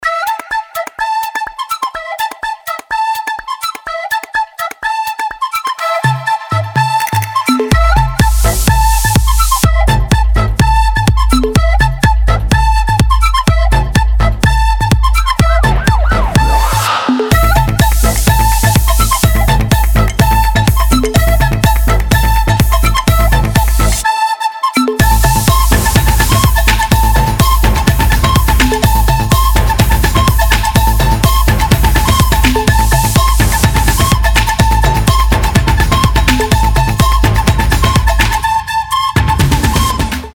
• Качество: 320, Stereo
свист
dance
без слов
club
инструментальные
house
Навсвистывание на чем-то вроде флейты под кубную хаус-музыку